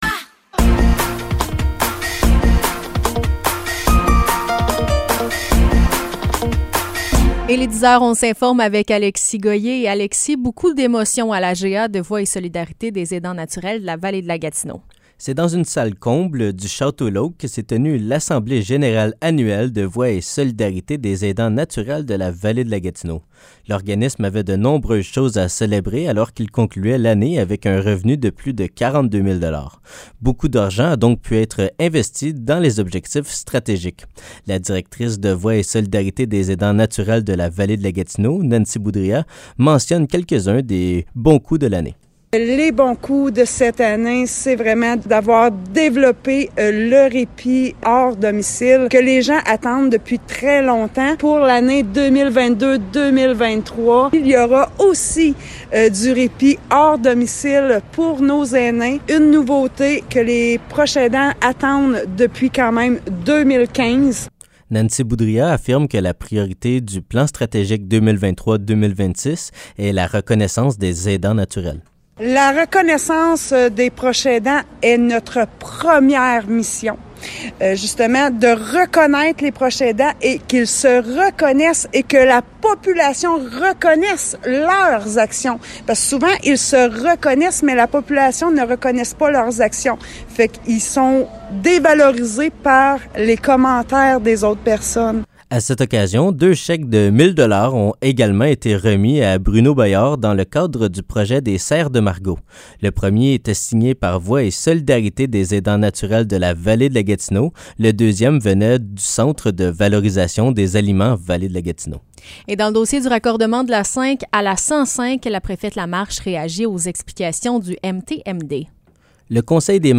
Nouvelles locales - 4 juillet 2023 - 10 h